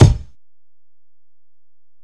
Kick (26).wav